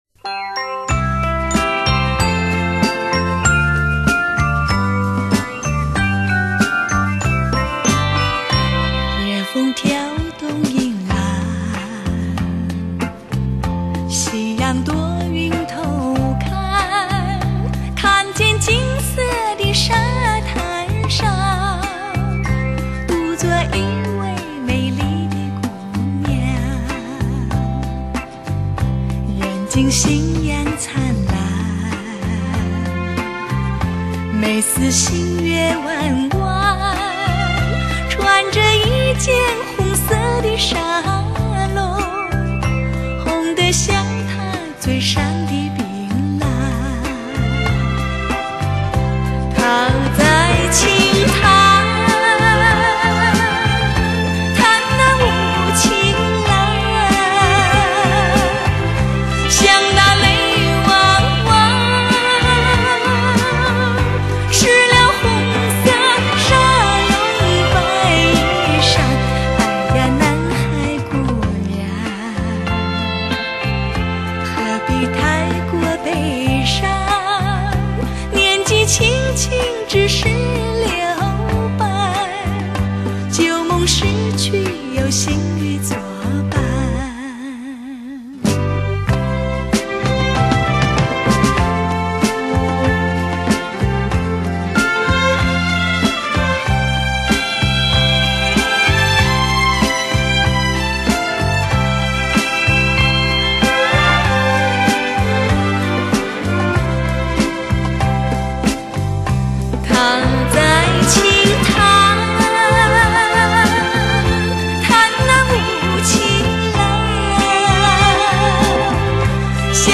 许多年前，一代人被她甜美歌声深深陶醉